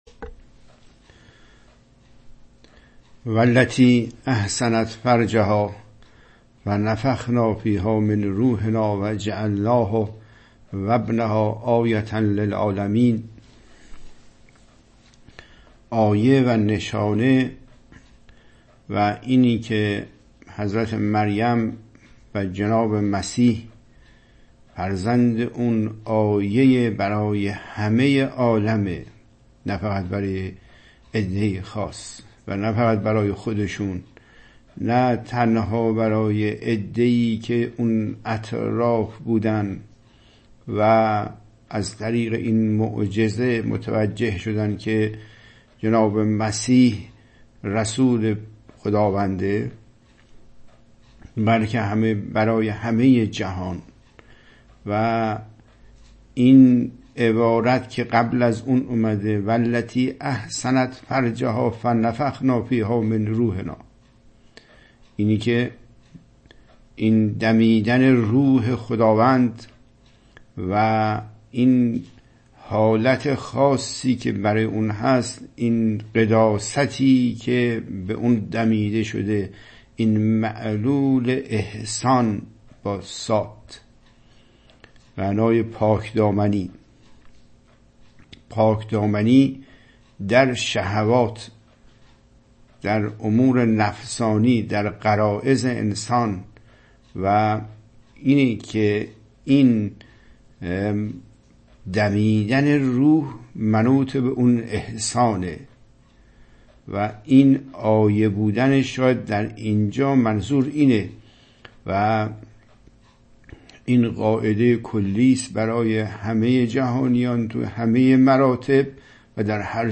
برنامه جدید مواجهه با دنیای قرآنی اگرچه ادامه برنامه‌های قبلی است، اما مرتبه کامل‌تر آن بوده و از جهاتی با برنامه‌های قبلی متفاوت است: ۱. طبق روال قبلی برنامه انلاین آن با پخش آیاتی از قرآن مجید آغاز شده و حاضرین با مخاطبه و تکلیم با متن به توجه به نفس می‌پردازند.
۴. در جلسه ارائه شفاهی صورت خواهد گرفت ولی سوال مکتوب یا شفاهی طرح نخواهد شد.